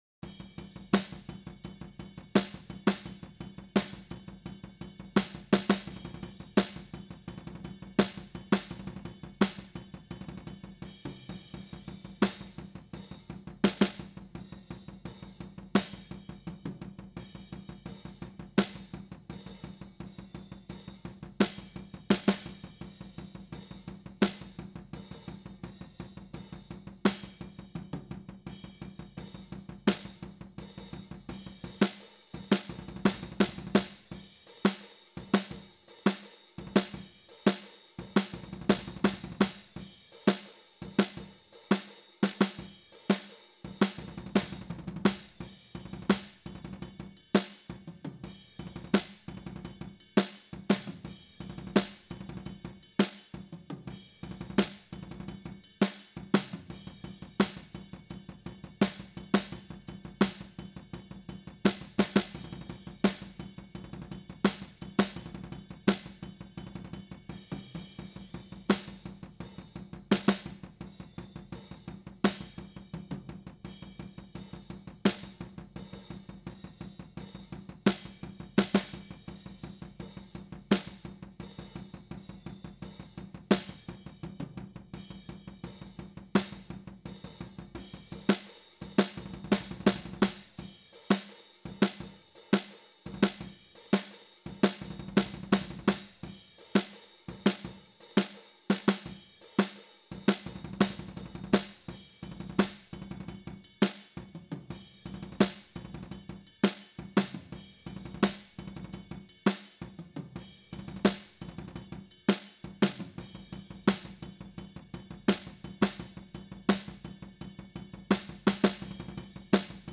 humanized-Snare_bottom-8.wav